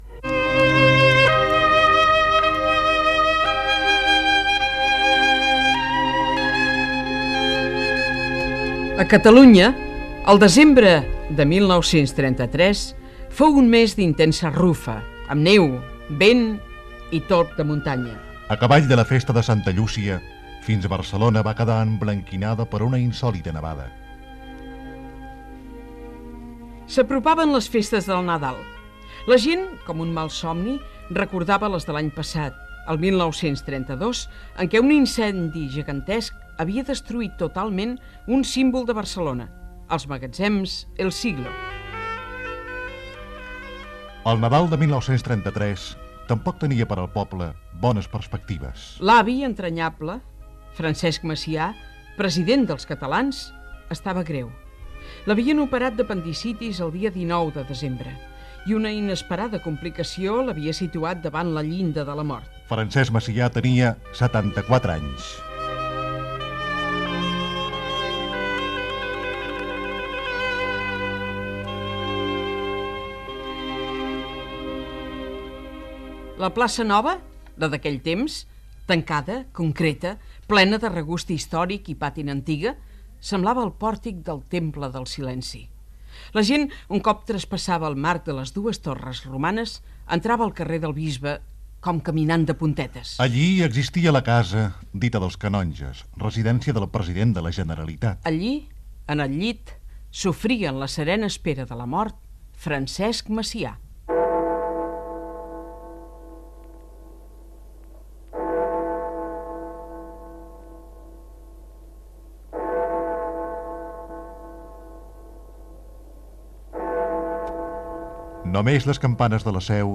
Presentador/a
FM